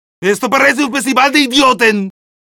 Kategori Lydeffekt